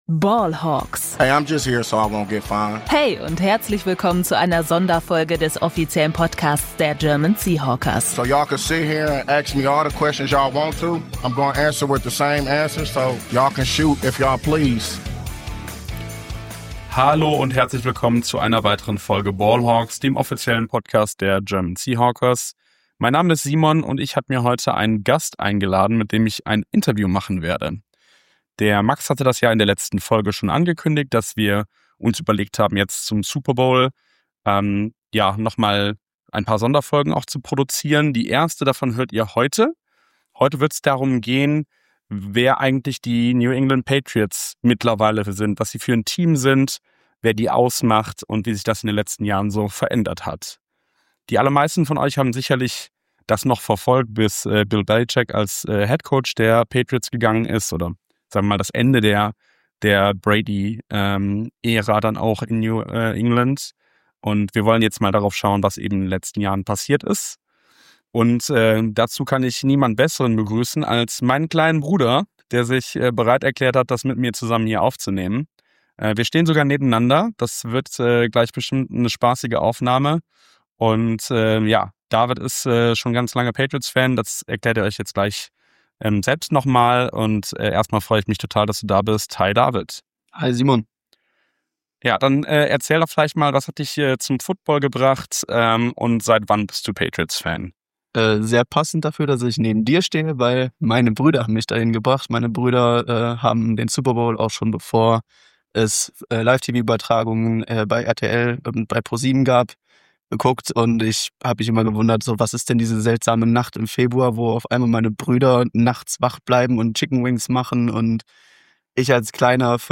Podcast der German Sea Hawkers e.V.